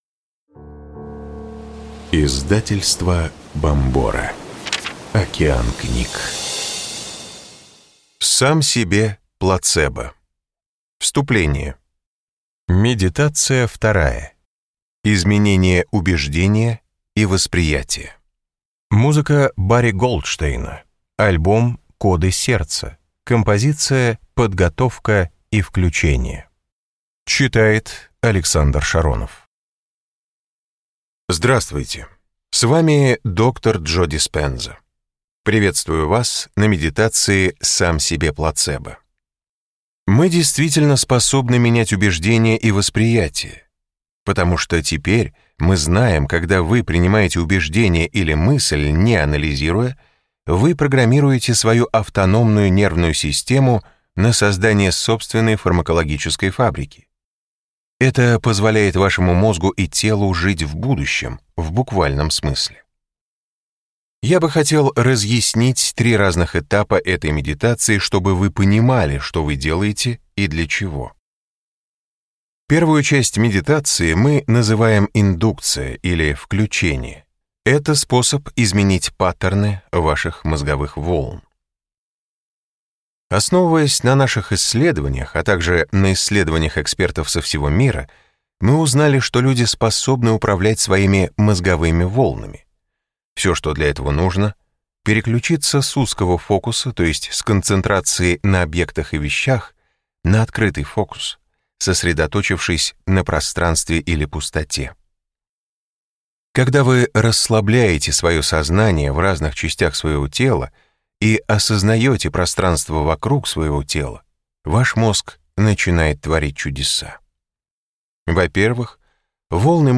Аудиокнига Сам себе плацебо: Медитация 2. Изменение убеждений и восприятия | Библиотека аудиокниг